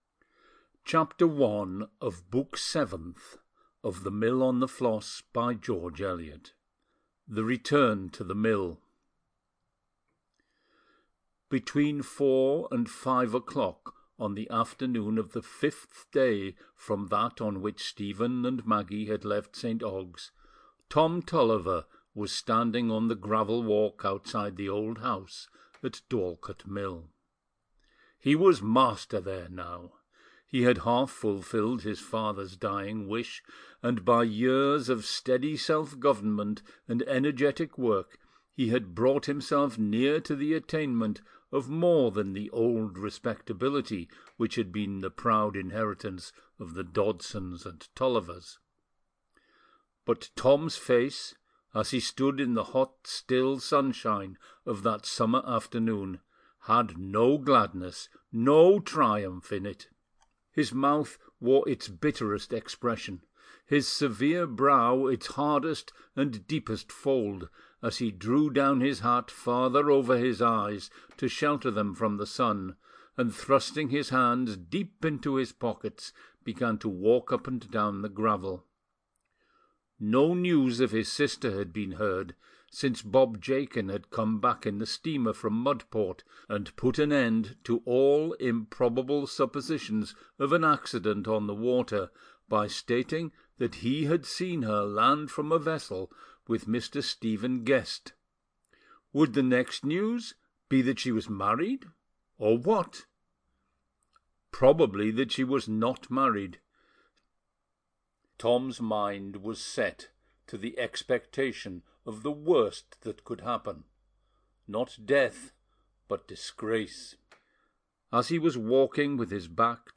This audiobook delivers the final, emotionally sweeping movement of The Mill on the Floss, where everything that has been quietly building finally collides. Love, loyalty, regret, and moral courage converge with an intensity modern movies thrive on.